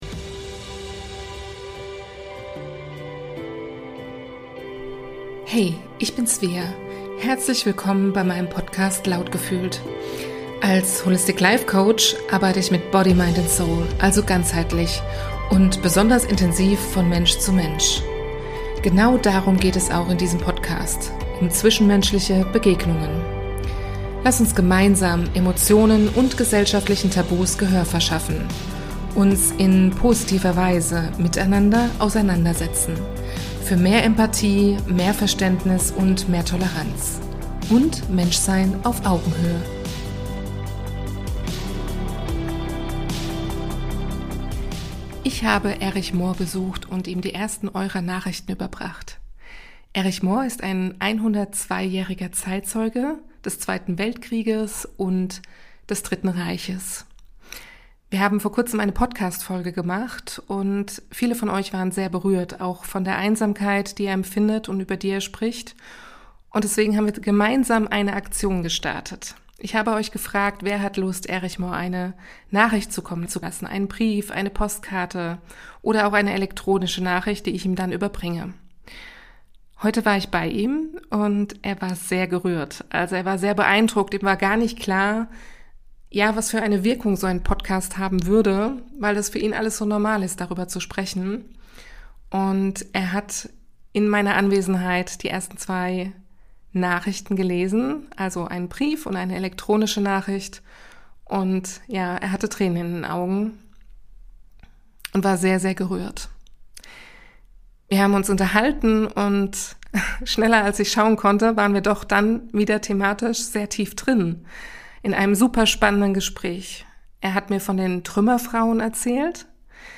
Und wir sind direkt wieder in ein sehr interessantes Gespräch gekommen und haben zwischendurch beschlossen, es spontan über mein Handy aufzuzeichnen.